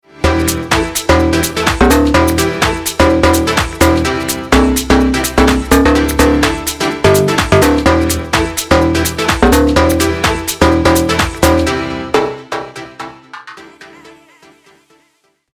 Помогите накрутить Bass